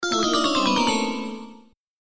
Level_Fail_1.mp3